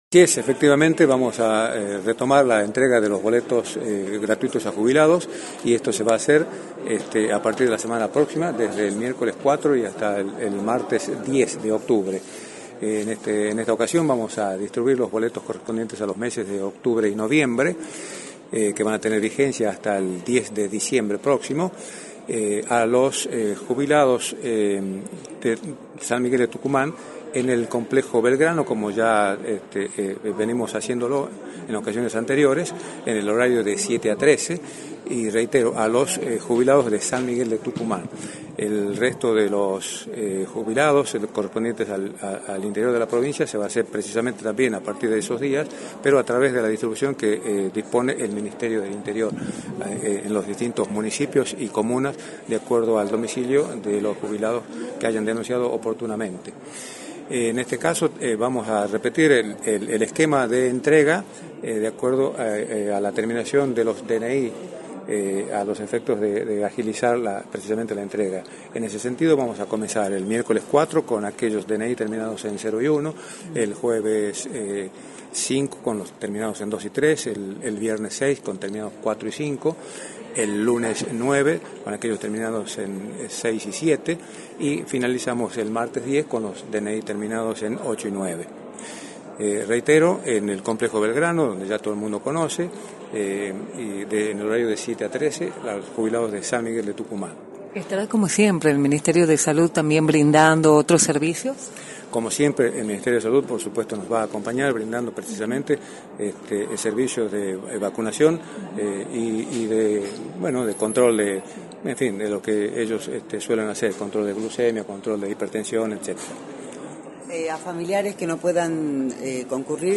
Vicente Nicastro, Secretario de Transporte, indicó en Radio del Plata Tucumán, por la 93.9, que a partir del 4 de octubre se retomará con la entrega de boletos gratuitos para los jubilados de San Miguel de Tucumán.